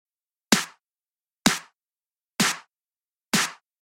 Tip 3: Distortion
In the example I have been working through I think the clap could do with a bit more emphasis, so I’ve loaded up the standard Cubase distortion, and added just a tiny amount to the clap/snare group – you can hear a big difference: